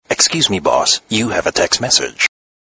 Звуки уведомлений Андроид